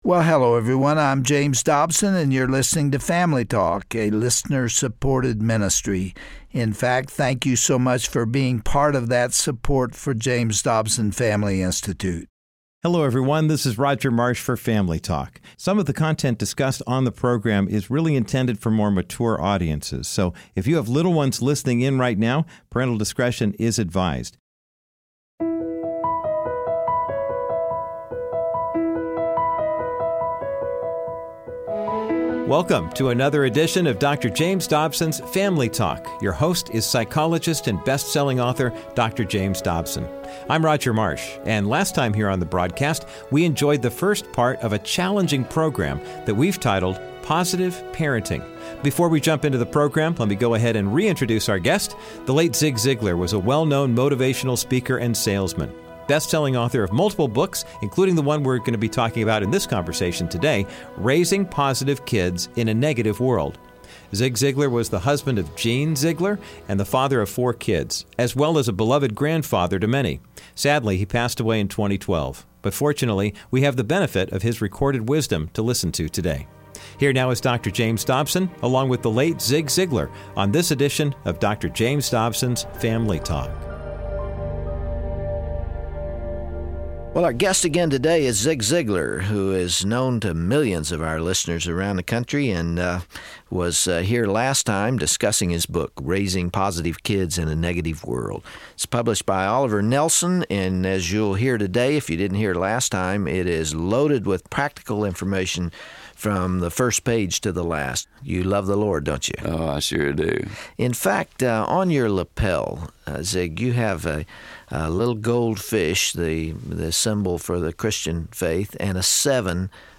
Parenting in the 21st century is extremely difficult because of the rampant immorality that threatens the hearts and minds of our kids. Listen to this timeless Family Talk broadcast to hear why raising God-fearing children is still possible despite this wayward society. Dr. Dobson and the late Zig Ziglar focused on the perversion of sex by culture, and then described a healthy and biblical view of intimacy and love.